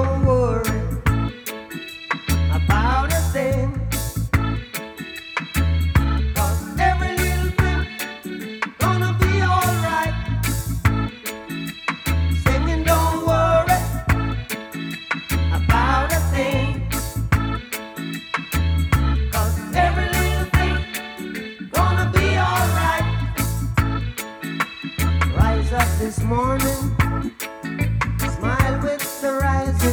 • Reggae